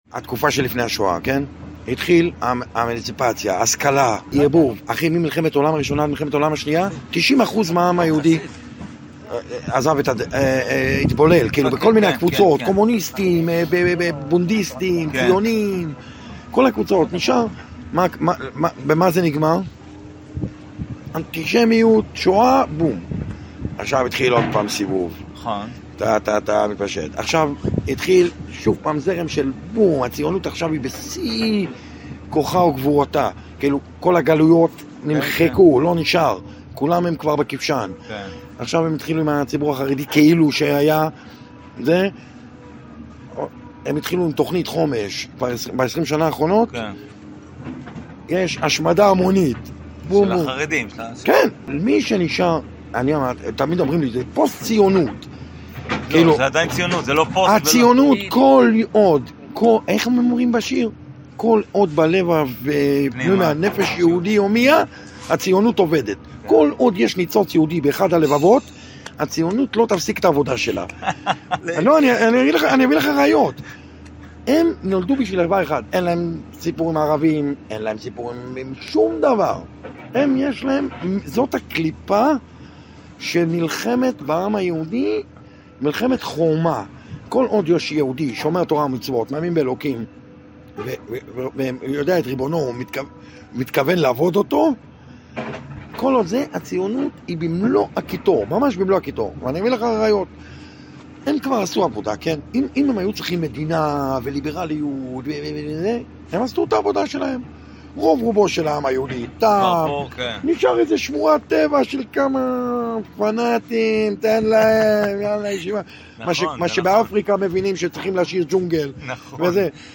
שיחה
חלק מהקלטה של שיחה ספונטנית